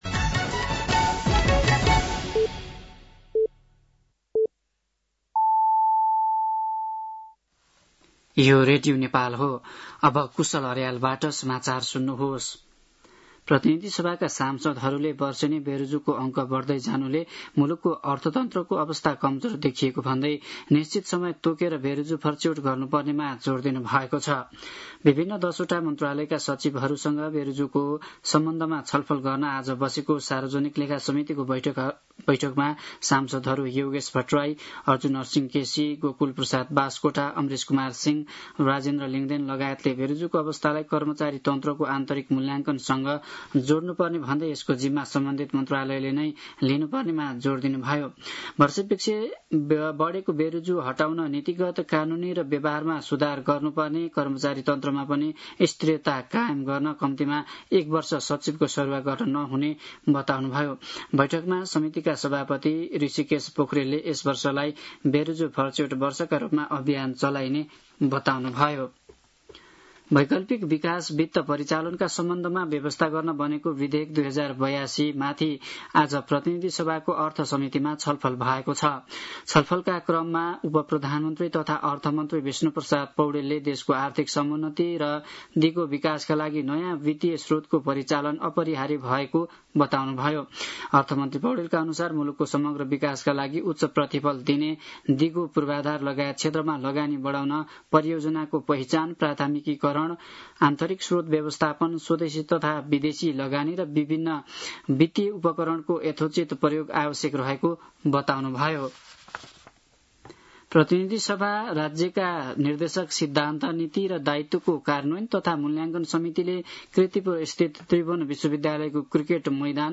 साँझ ५ बजेको नेपाली समाचार : २३ साउन , २०८२
5-pm-nepali-news-4-22.mp3